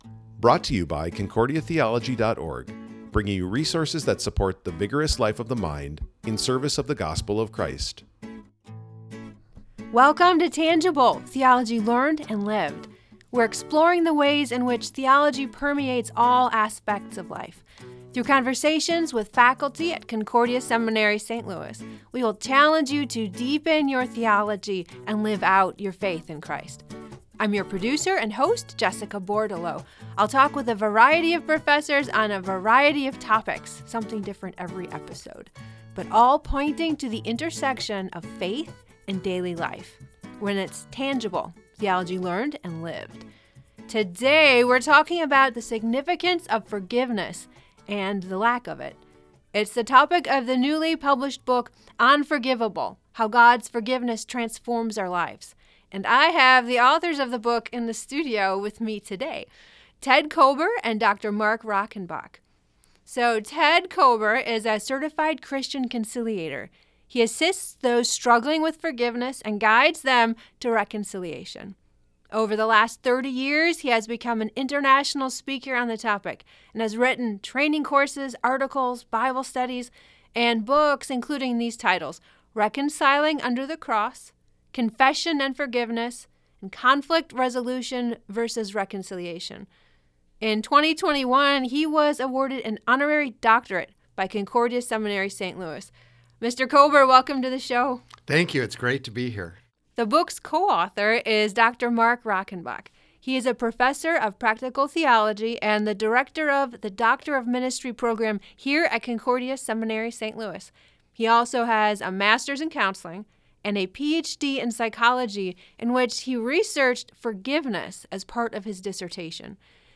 Opening Service (video/mp4, 222.0 MB)